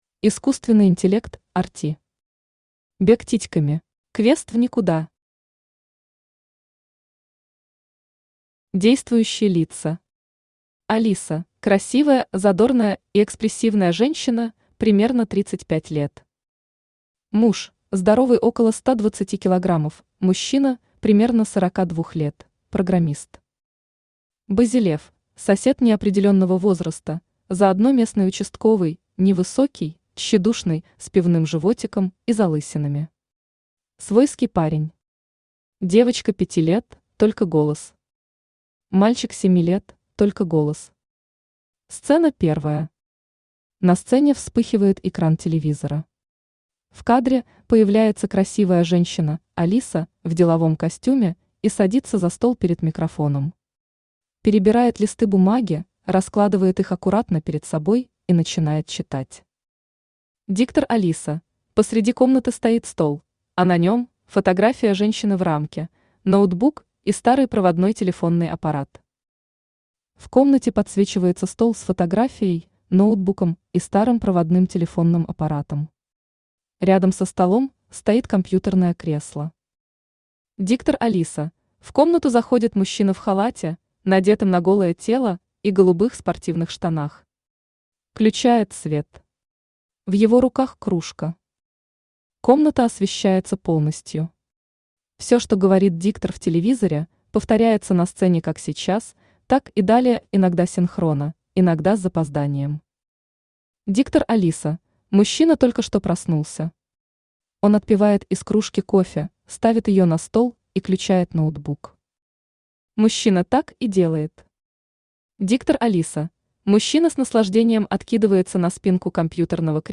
Aудиокнига Бег титьками Автор Искусственный Интеллект RT Читает аудиокнигу Авточтец ЛитРес. Прослушать и бесплатно скачать фрагмент аудиокниги